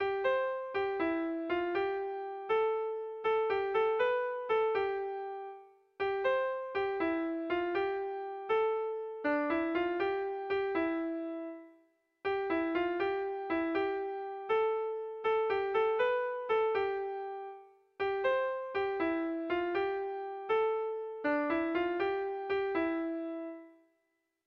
Kontakizunezkoa
Zortziko txikia (hg) / Lau puntuko txikia (ip)
ABDB